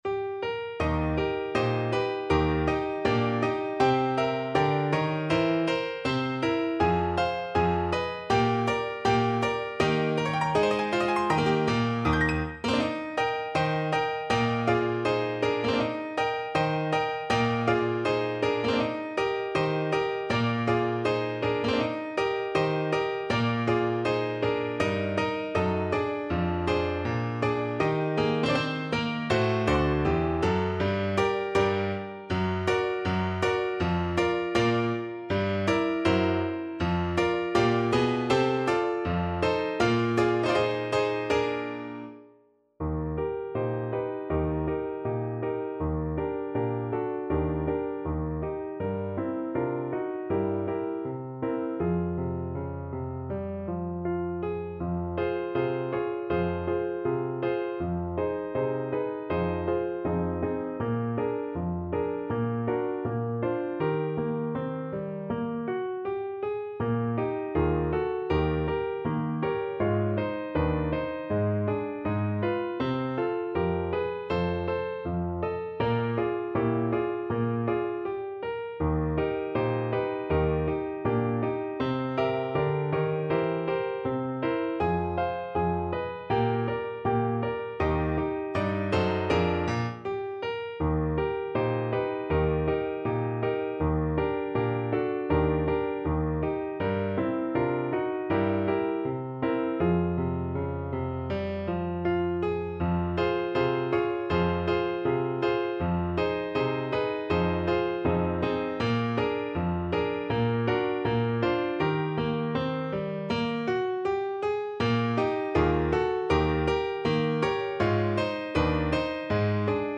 Moderato =80
Pop (View more Pop Clarinet Music)